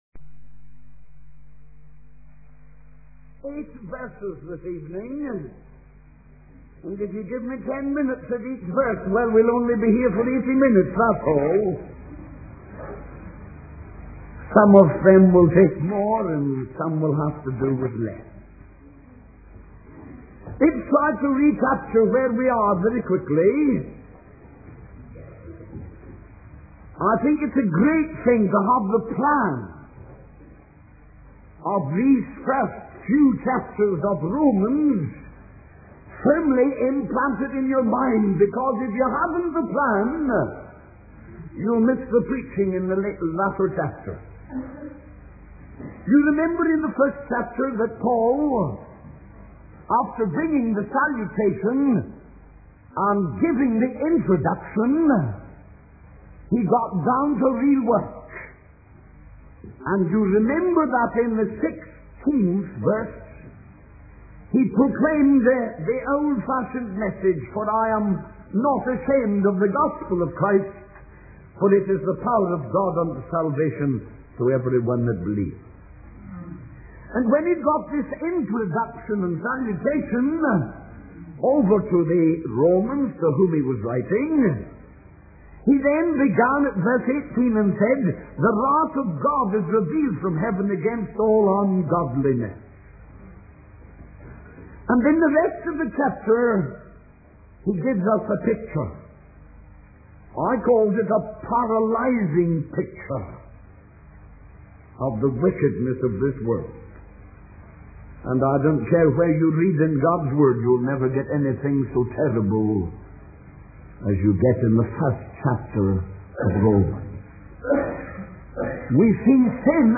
In this sermon, the preacher focuses on eight verses from the book of Romans, which he considers to be the greatest verses in the book. He discusses the wonders and wealth found in these verses, highlighting the wonder of inspiration and intuition.